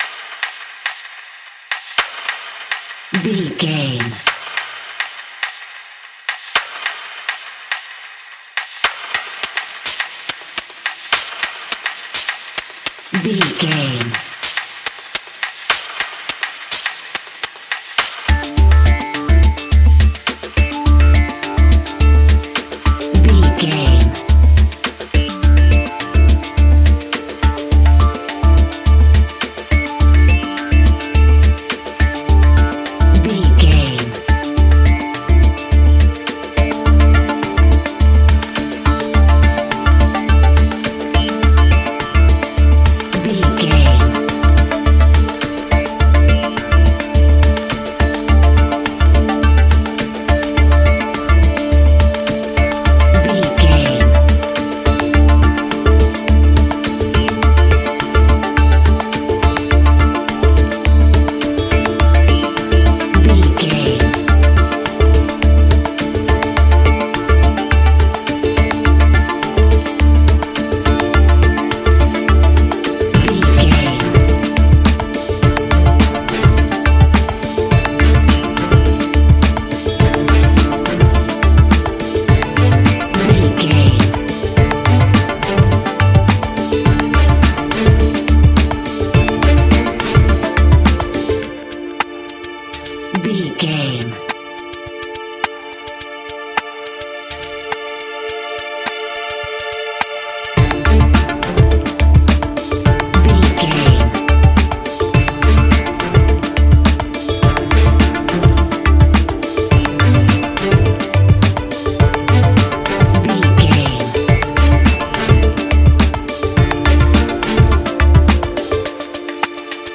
Ionian/Major
D
electronic
techno
trance
synths
synthwave
instrumentals